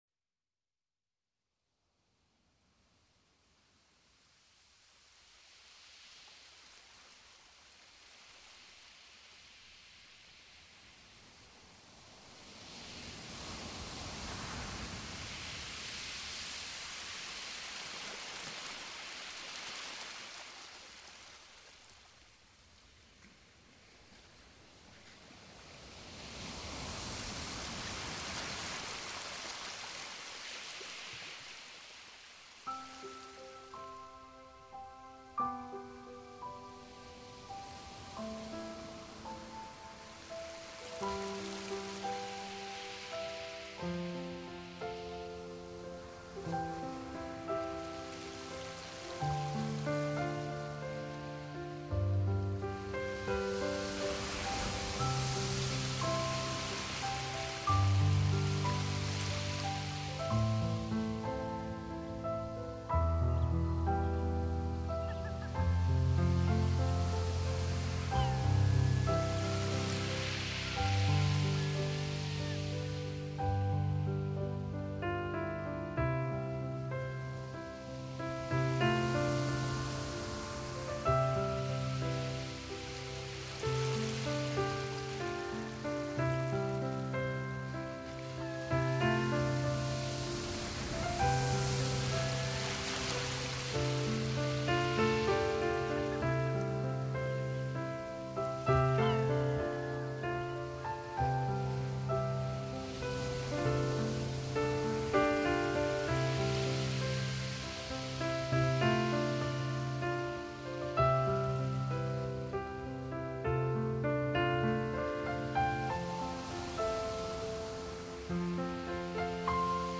音乐风格： New Age，Piano